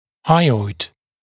[ˈhʌɪɔɪd][ˈхайойд]подъязычный, подъязычная кость